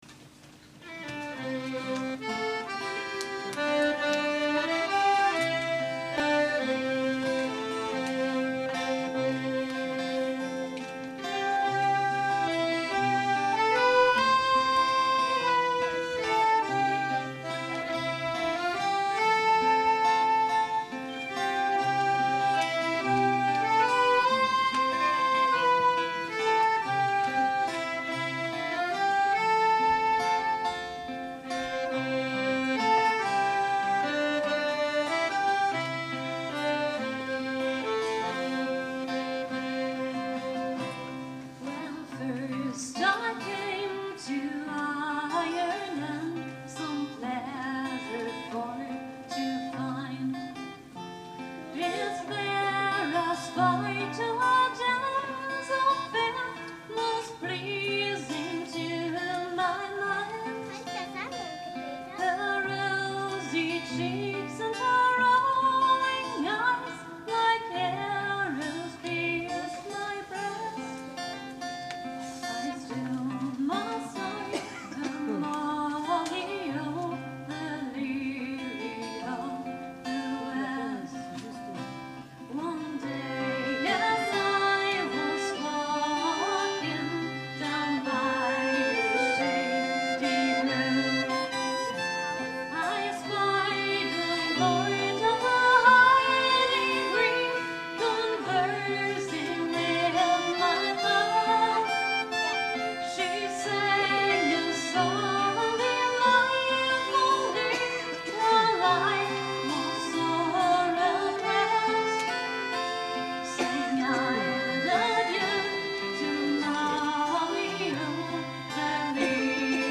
14.November 2004: Vereinskonzert
Irish-Folk-Band „Poppyhead“
traditional